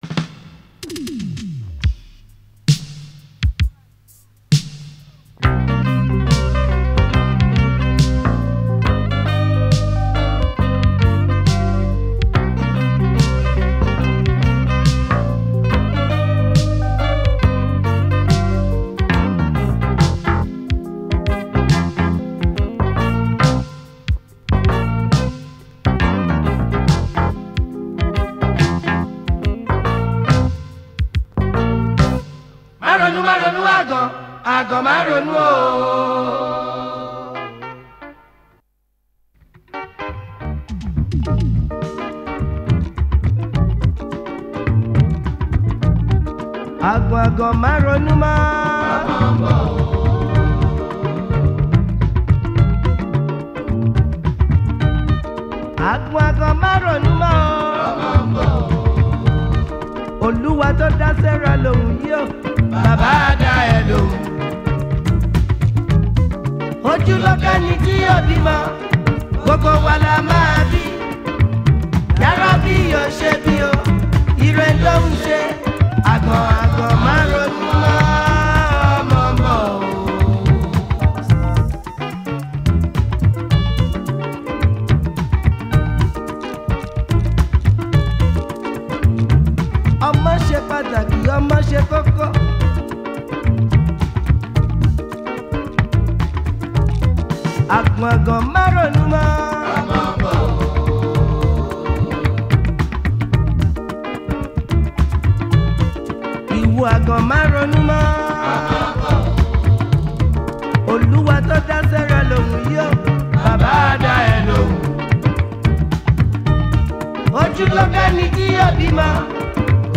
is a Nigerian Jùjú musician.